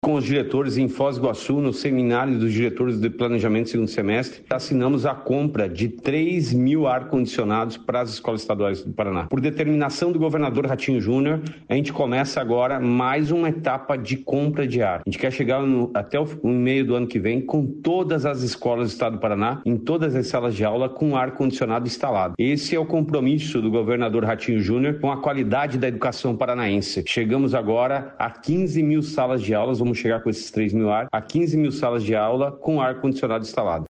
Sonora do secretário da Educação, Roni Miranda, sobre aparelhos de ar-condicionado para escolas dos Campos Gerais